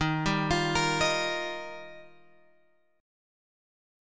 D#add9 chord {11 10 x 10 11 11} chord